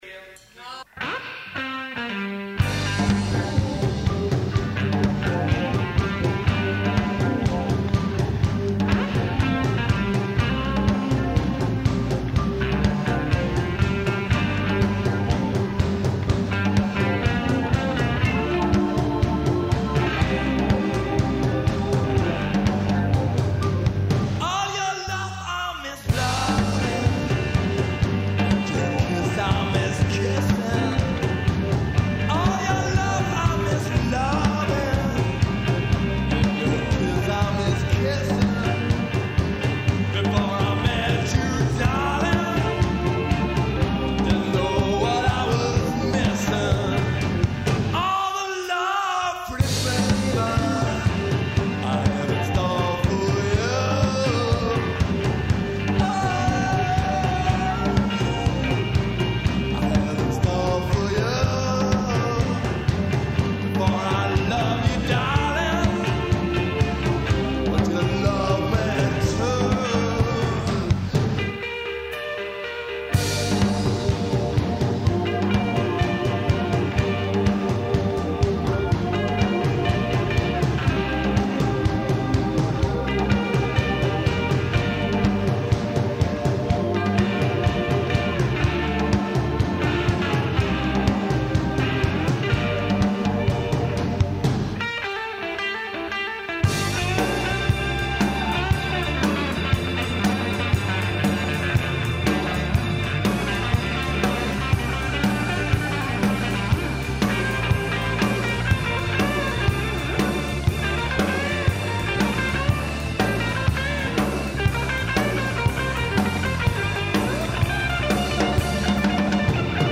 'rhythm and blues'
'Rhumba' beat